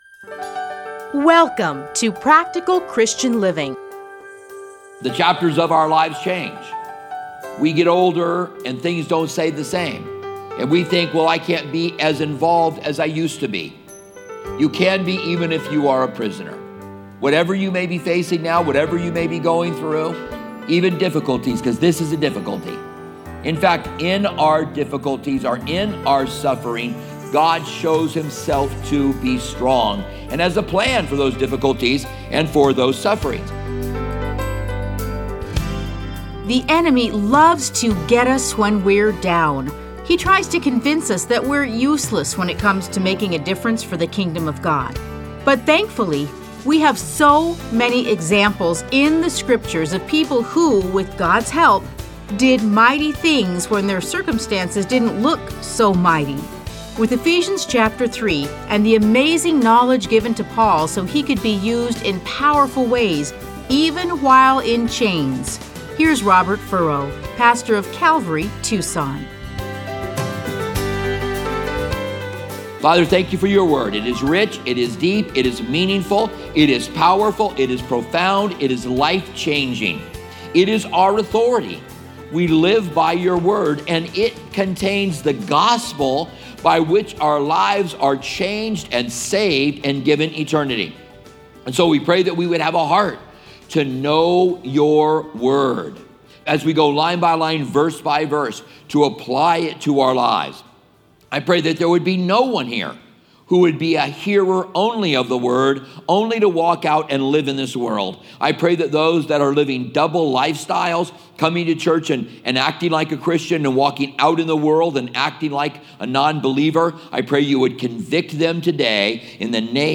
Listen to a teaching from Ephesians 3:1-13.